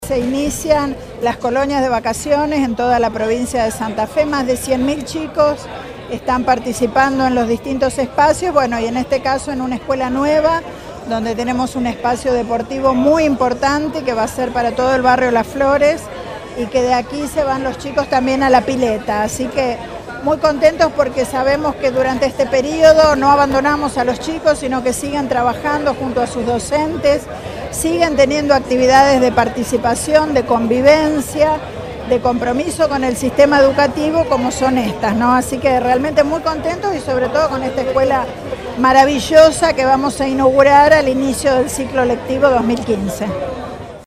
Declaraciones de la ministra de Educación, Claudia Balagué.